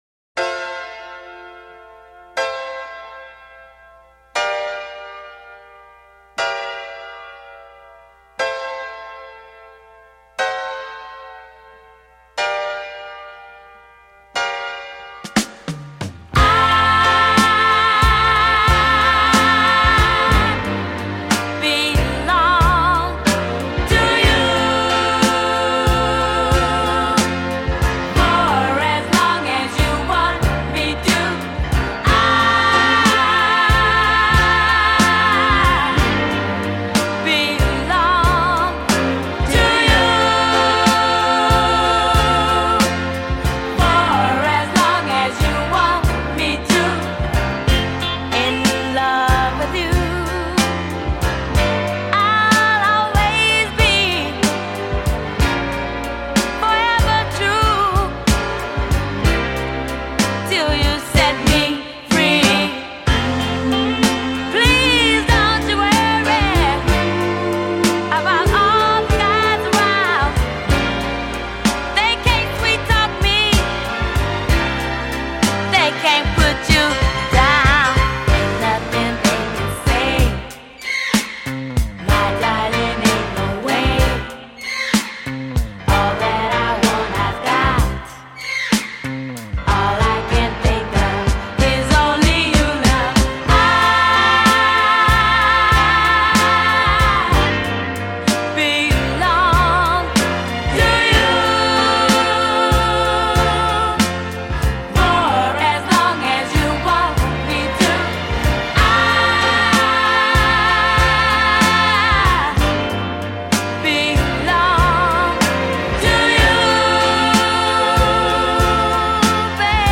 female trio
classy